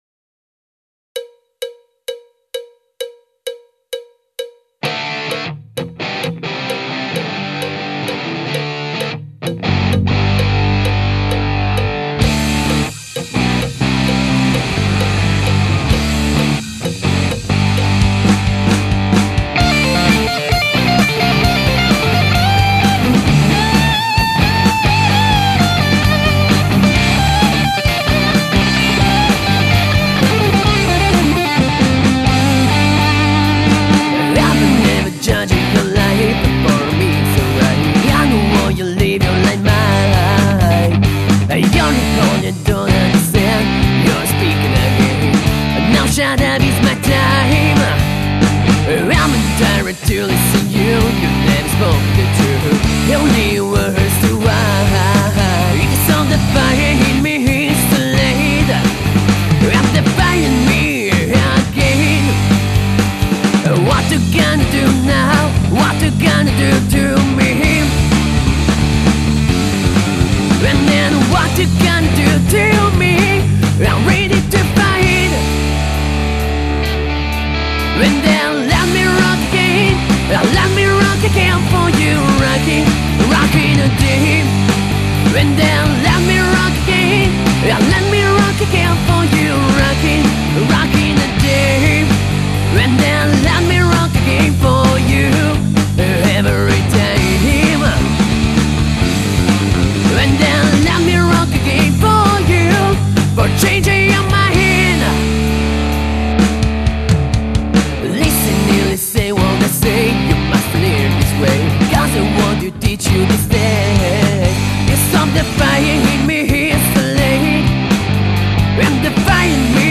Genere: Hard Rock
Batteria
Voce/Chitarra
Basso